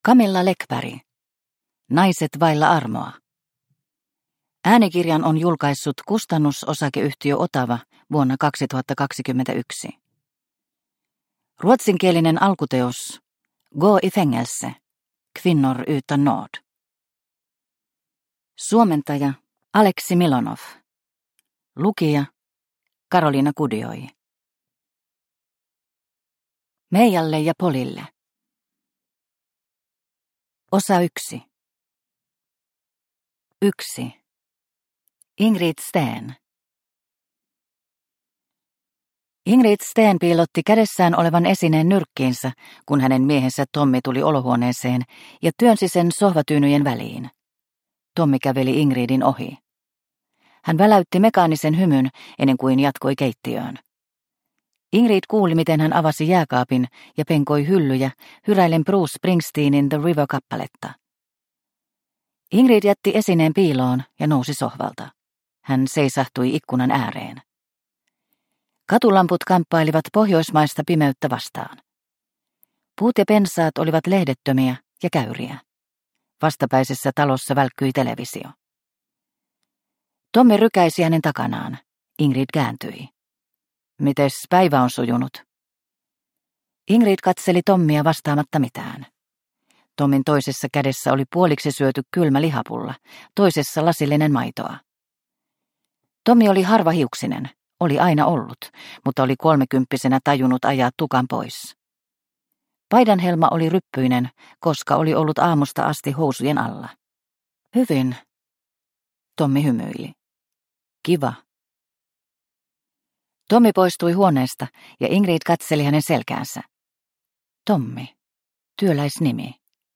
Naiset vailla armoa – Ljudbok – Laddas ner